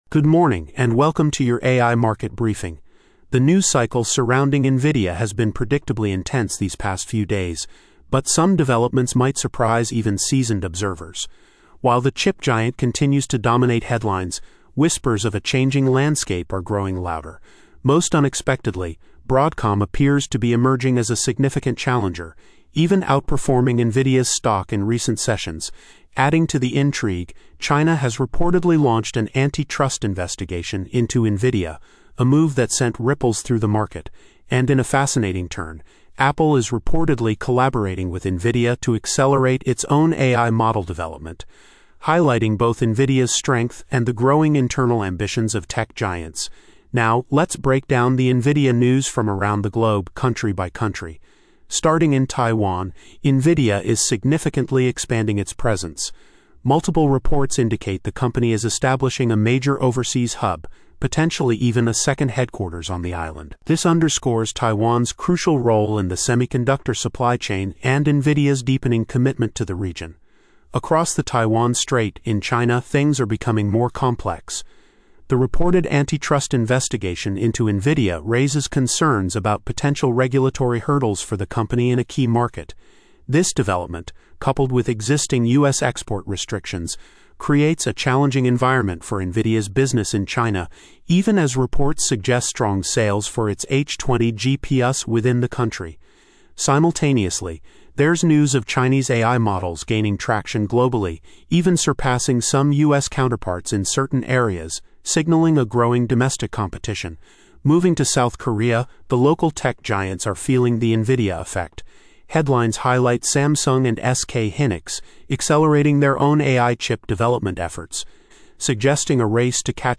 For those that just want to hear the final result, you can listen below to Gemini 2.0 Flash Thinking Experiment model's summarization of a selection of 250 global news headlines of articles from the past two weeks mentioning NVIDIA at least 3 times in the article, with the final summary rendered into the spoken word by GCP's Text-to-Speech en-US-Studio-Q model: